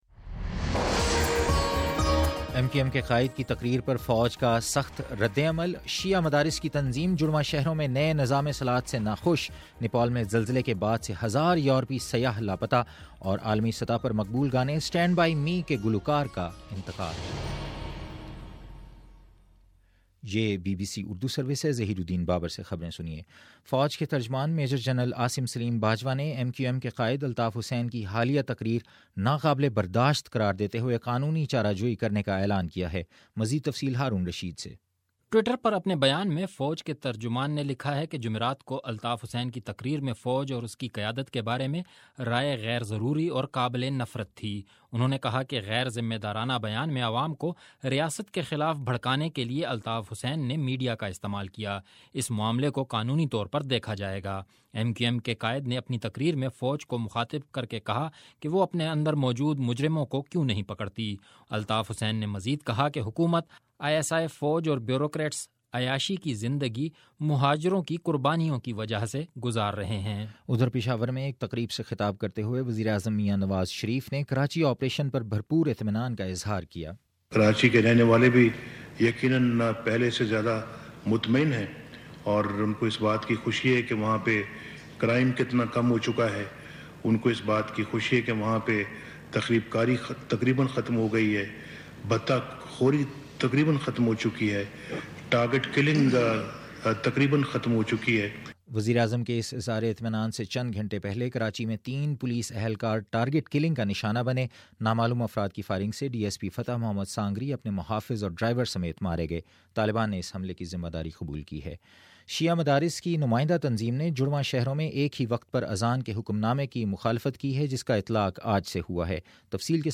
مئ 1: شام سات بجے کا نیوز بُلیٹن